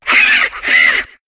Cri de Vaututrice dans Pokémon HOME.